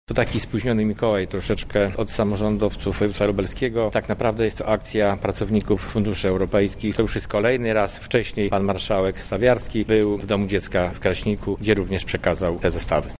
Akcja ma na celu wywołać uśmiech na twarzach dzieci, które w tym trudnym czasie i tuż przed gwiazdką przebywają w szpitalu- mówi członek Zarządu Województwa Lubelskiego, Zdzisław Szwed: